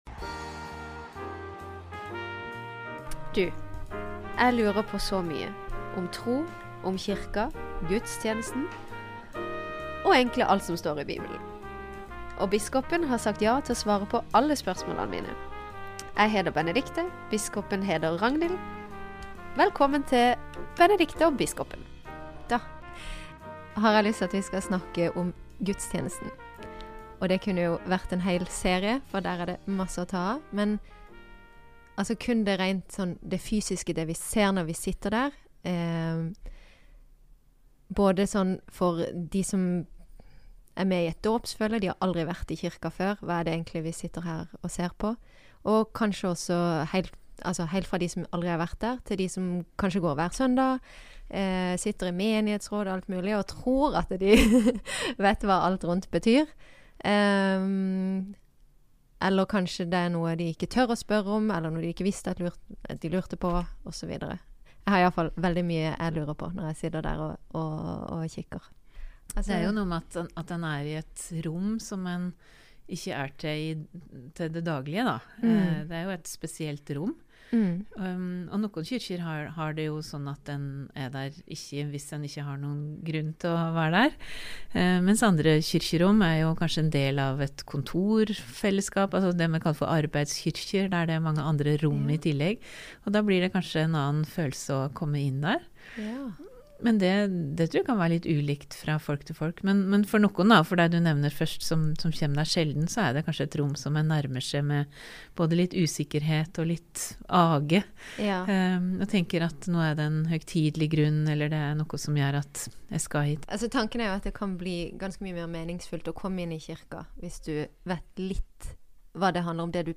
Samtalen handlar denne gongen om kyrkjerommet og gudstenesta. Kva skjer og kva betyr det ein kan sjå og oppleva der?